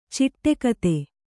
♪ ciṭṭe kate